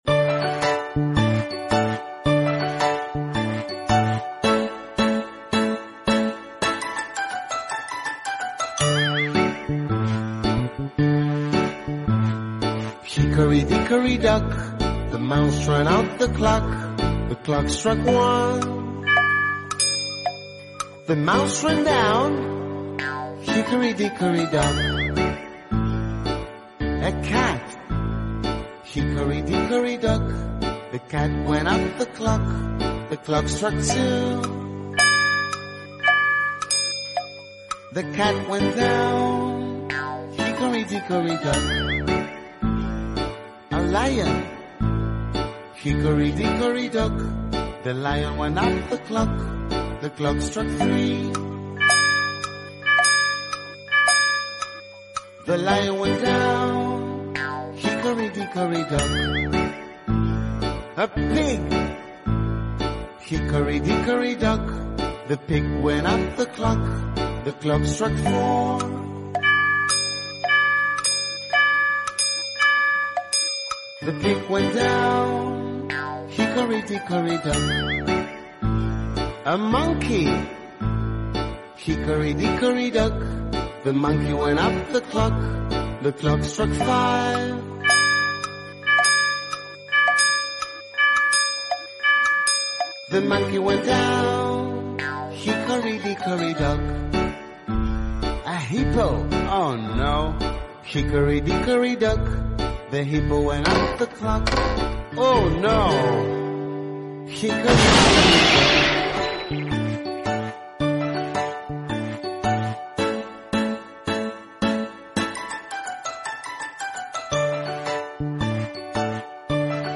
elephant broke the clock sound effects free download